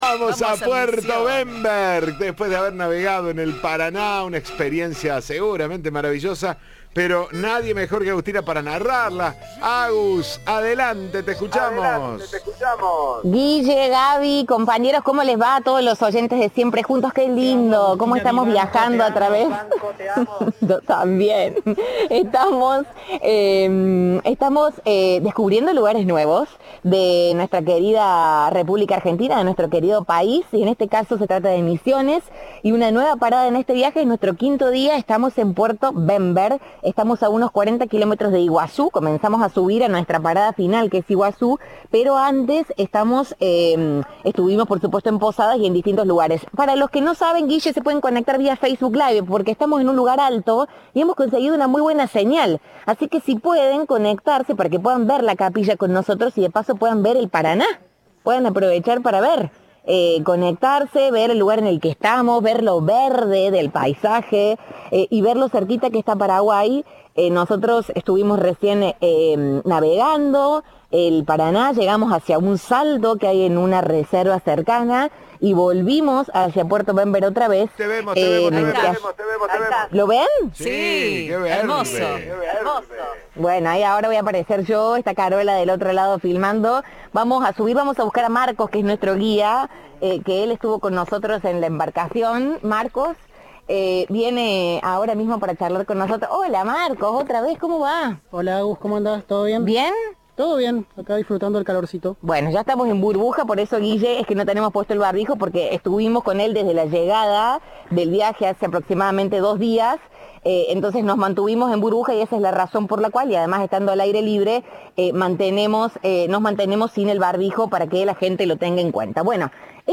Reviví la transmisión del Facebook Live.